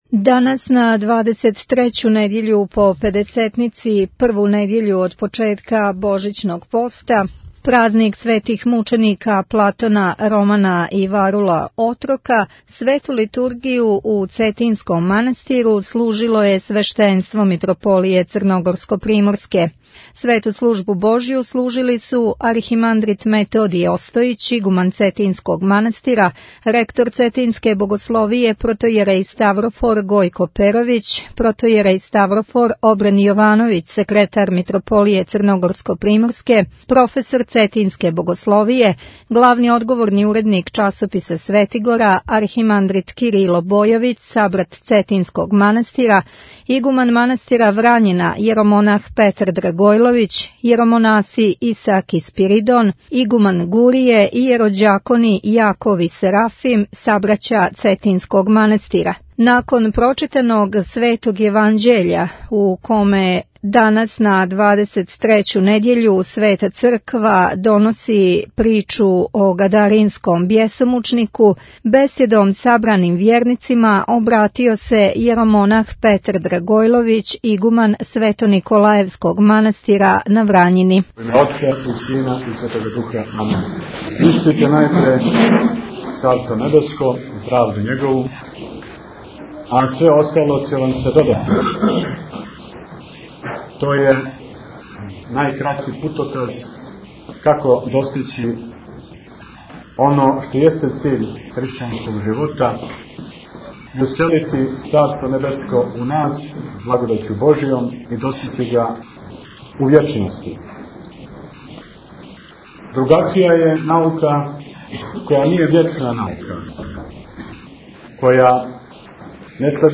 Бесједа